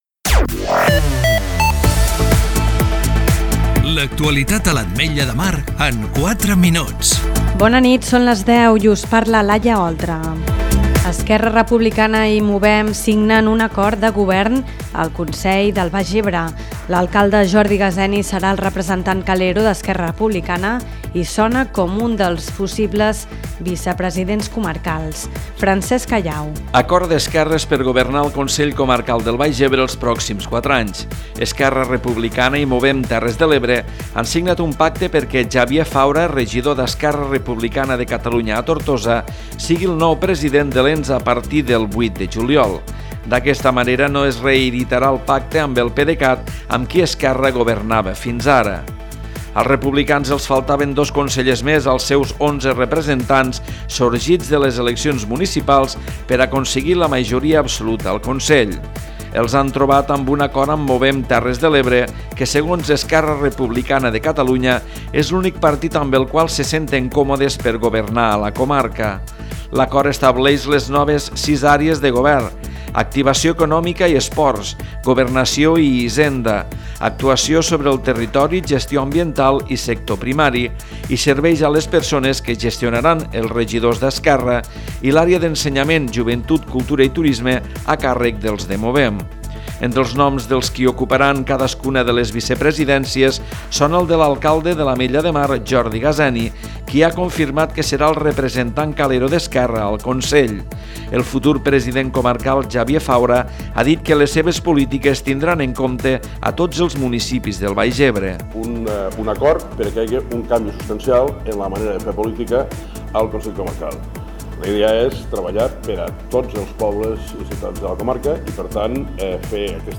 Butlletí 22 h (28/06/2019)